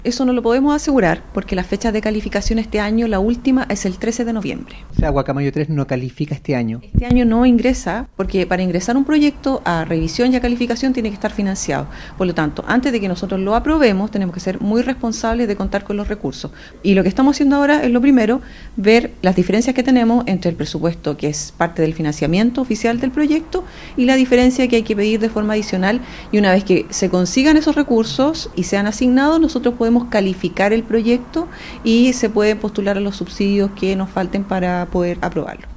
La directora regional del Serviu en Los Ríos, Nebenka Donoso, explicó a Radio Bío Bío en Valdivia que han sostenido mesas de trabajo con la empresa constructora y la entidad patrocinada para resolver observaciones técnicas y económicas del proyecto, así como también las exigencias de su Resolución de Calificación Ambiental, que se otorgó en mayo de este año.